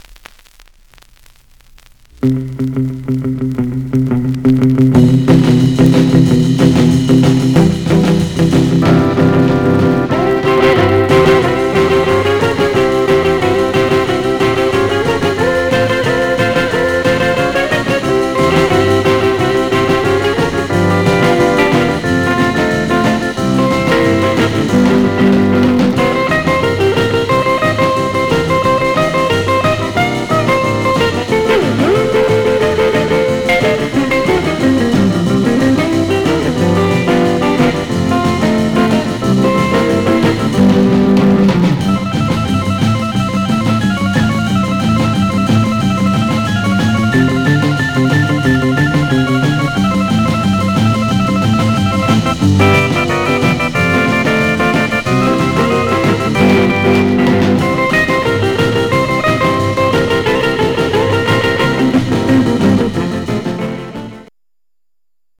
Some surface noise/wear
Mono
R & R Instrumental